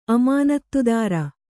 ♪ amānattudāra